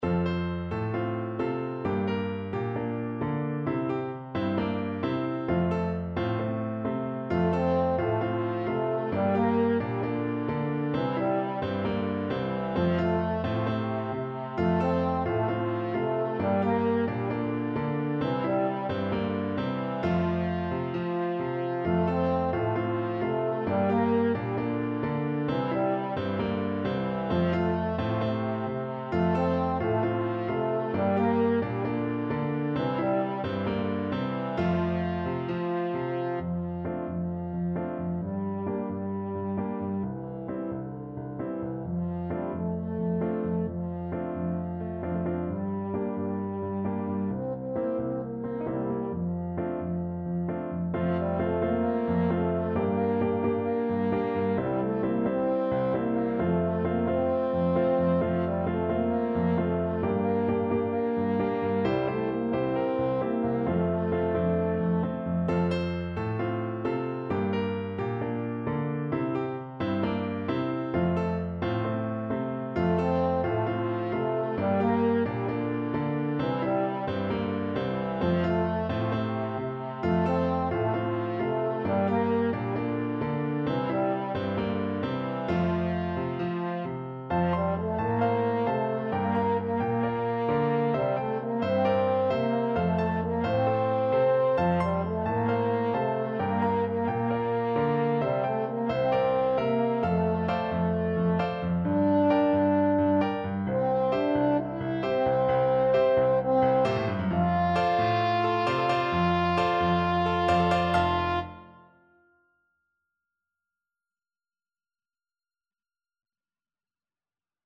Free Sheet music for French Horn
Calypso =c.132
F major (Sounding Pitch) C major (French Horn in F) (View more F major Music for French Horn )
4/4 (View more 4/4 Music)
Instrument:
Traditional (View more Traditional French Horn Music)